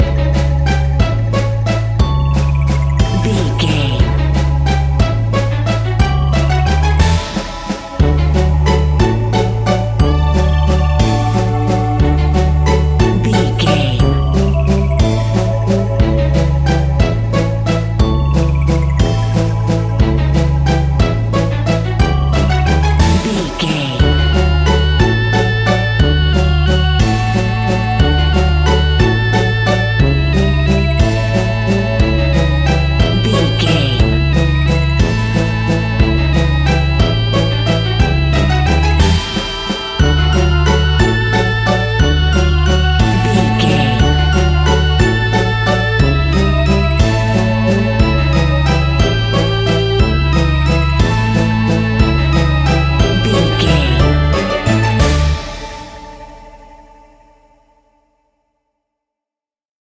Aeolian/Minor
D
scary
ominous
haunting
eerie
playful
strings
synthesiser
percussion
spooky
horror music